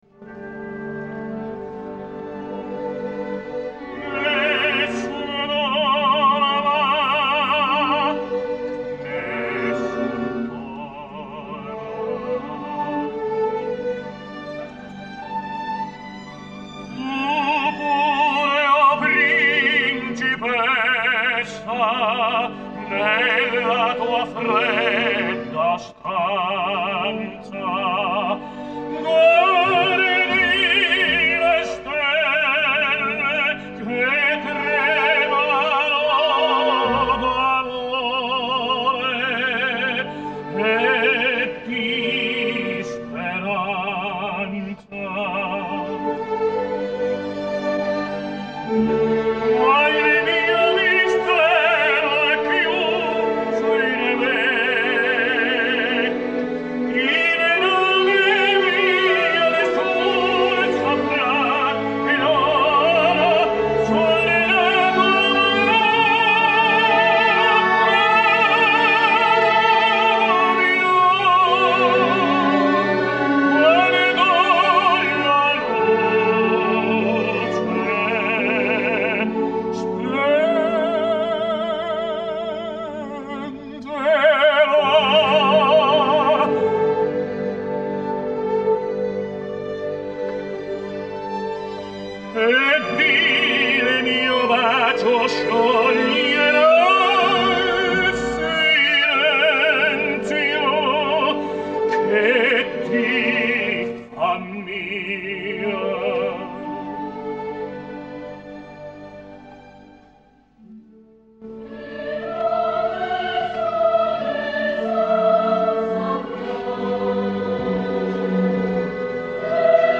Tenors singing Nessun dorma